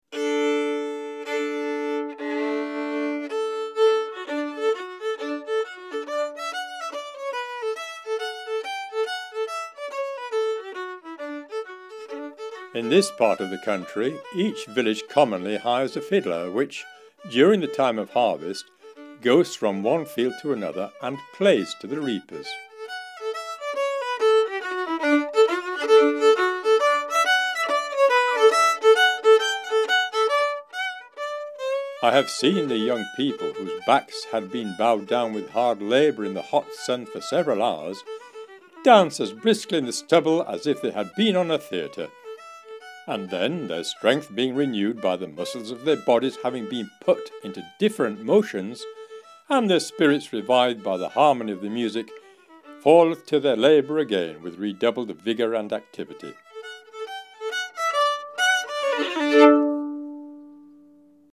provided the voice of John Lucas
gave a rustic rendition of the hornpipe ‘Harvest Home’